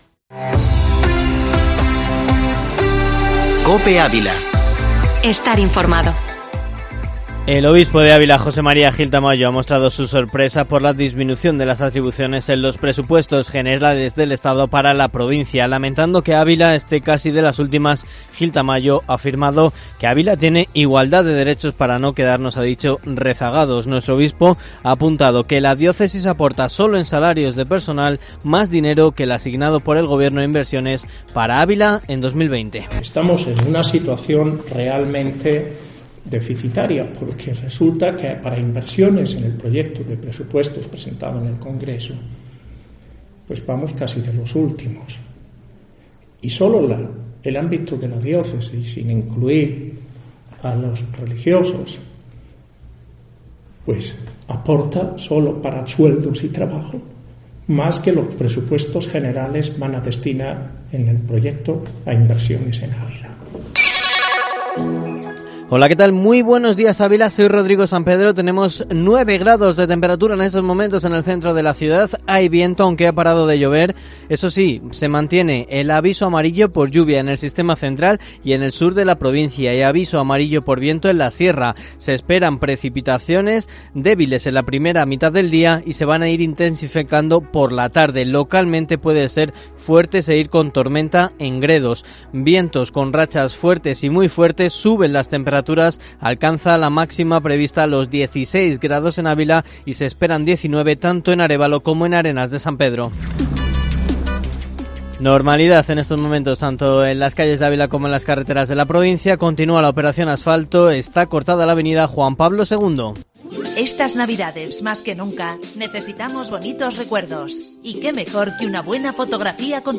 Informativo matinal Herrera en COPE Ávila 06/11/2020